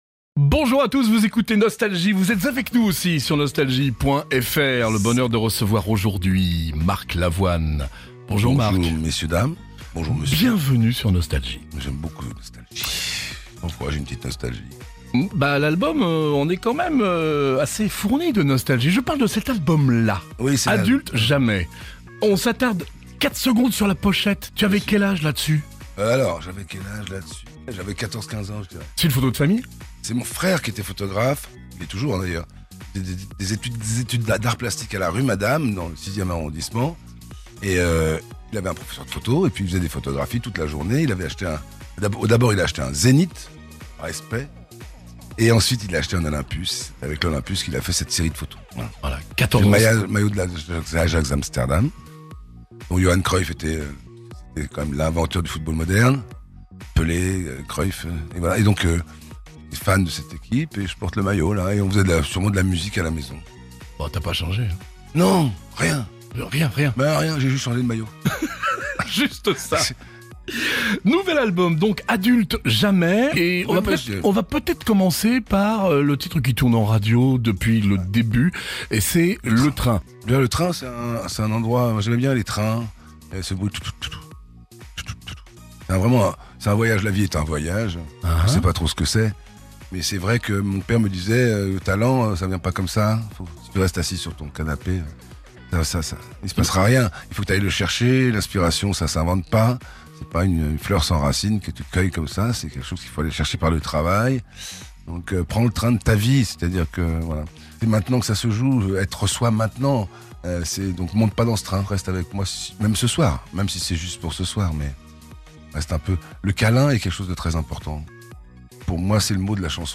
Les interviews
Les plus grands artistes sont en interview sur Nostalgie.